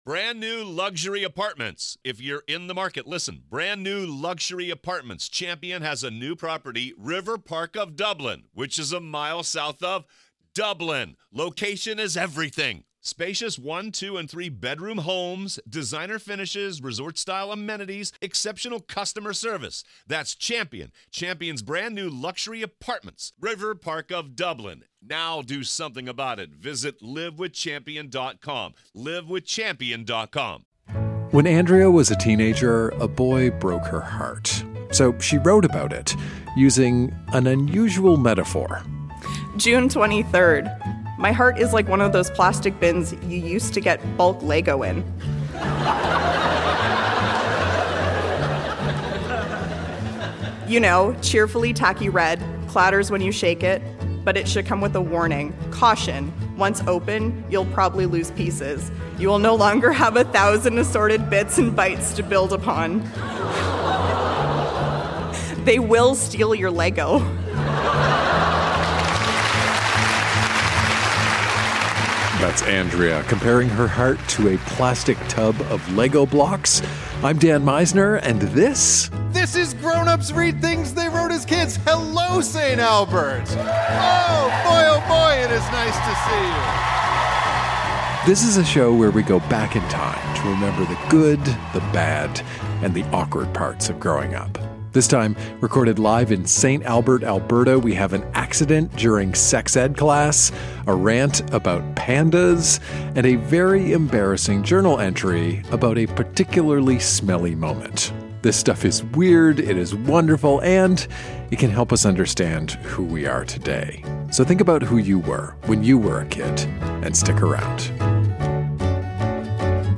Recorded live at The Arden Theatre in St. Albert.